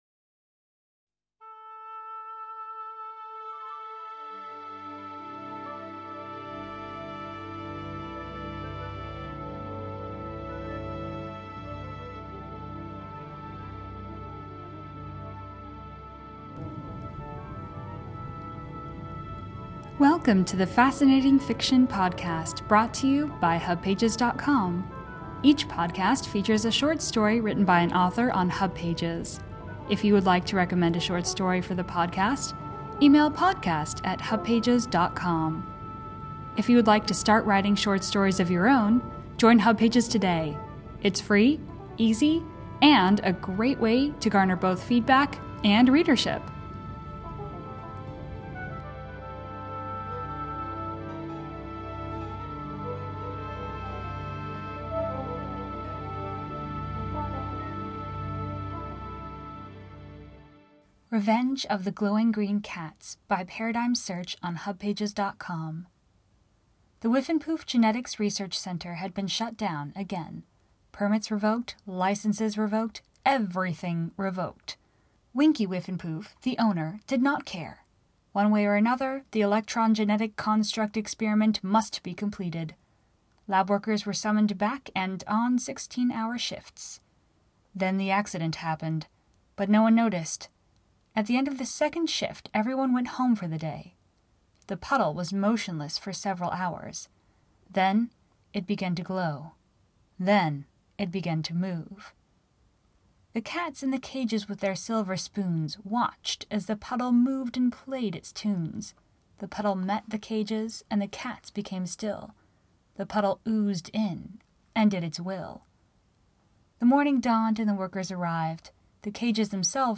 podcast version of this short story.